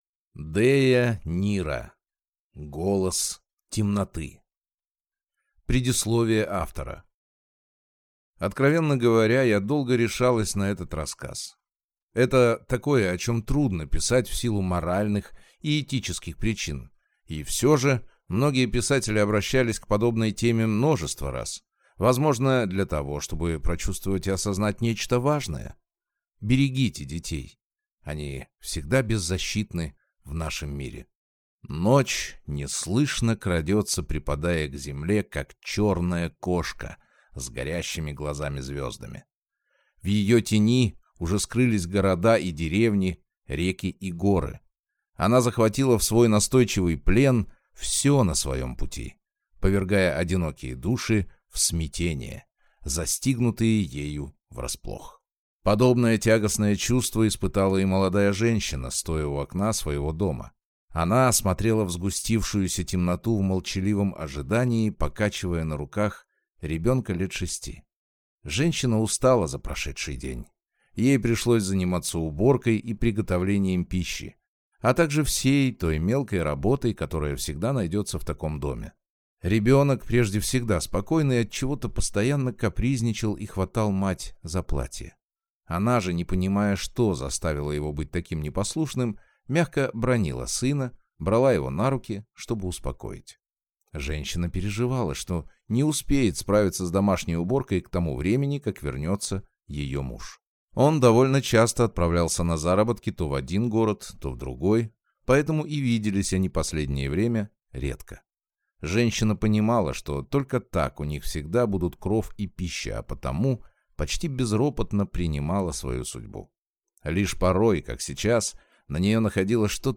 Аудиокнига Голос темноты | Библиотека аудиокниг